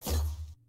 激光脉冲1.ogg